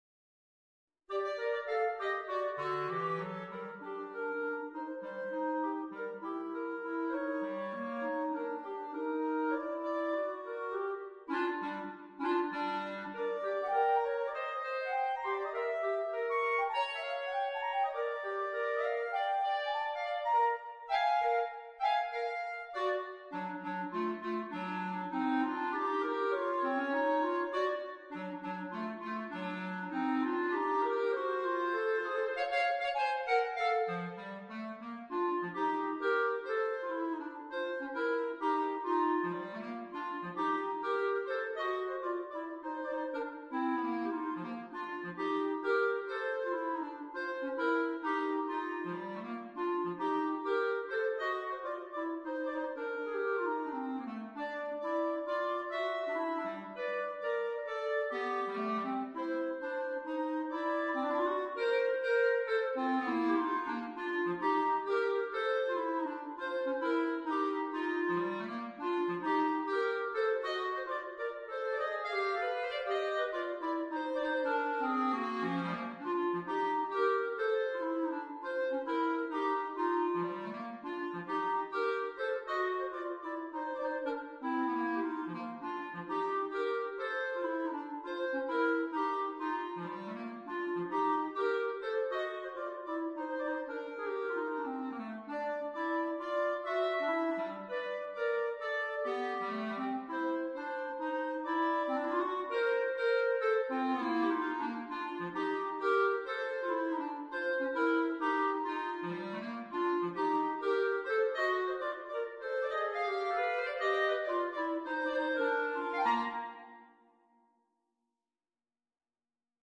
per due clarinetti